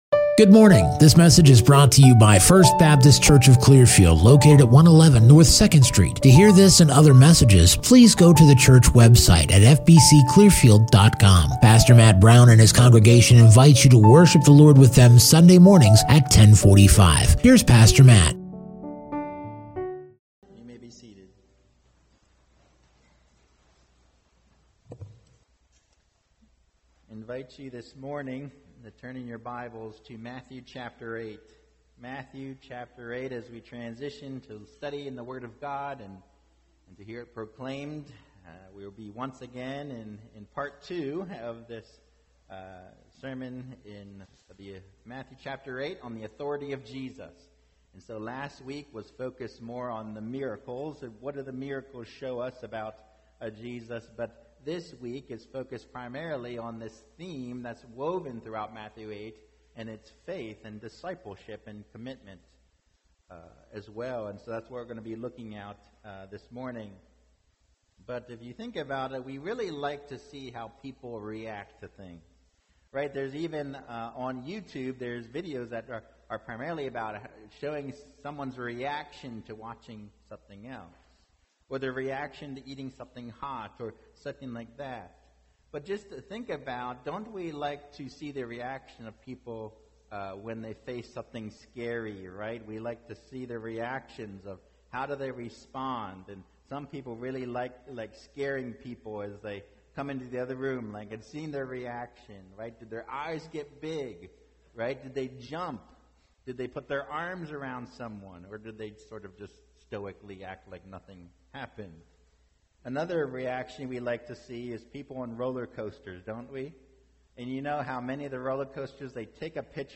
Part 2 Preacher